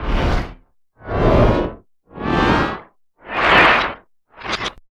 99 PAN FX -R.wav